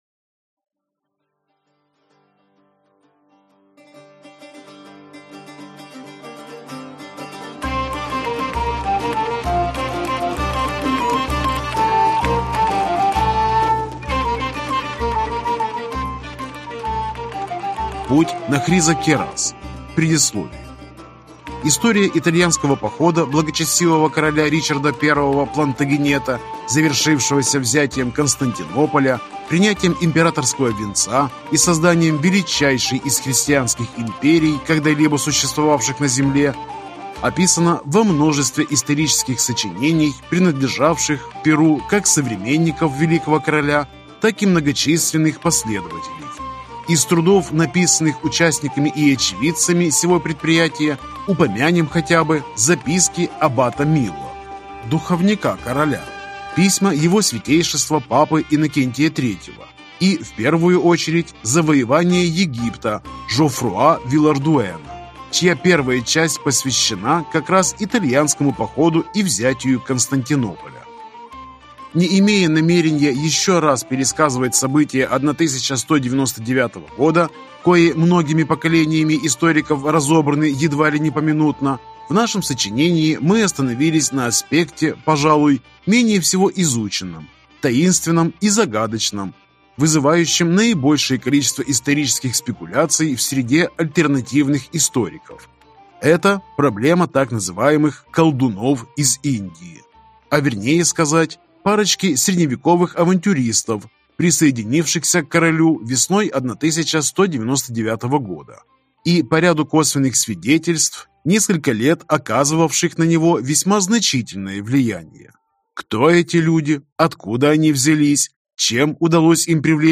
Аудиокнига Путь на Хризокерас | Библиотека аудиокниг